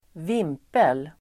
Ladda ner uttalet
Uttal: [²v'im:pel]